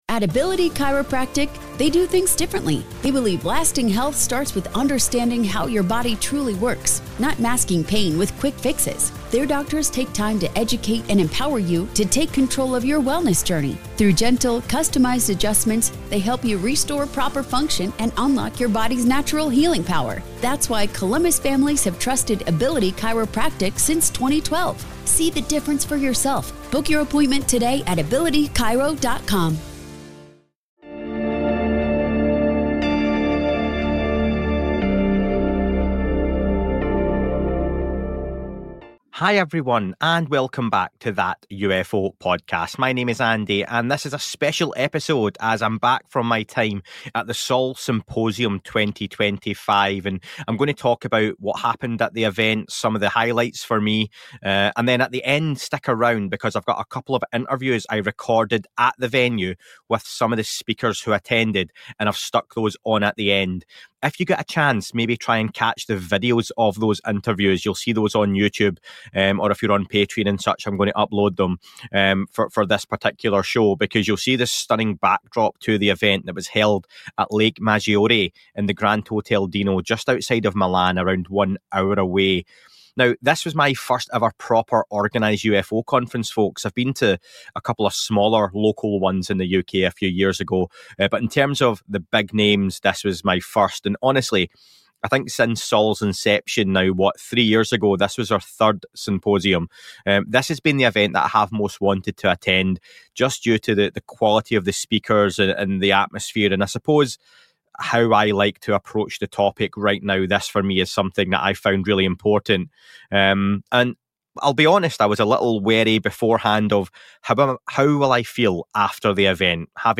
Sol Symposium 2025: Event Highlights, Key Speakers, and Exclusive Interviews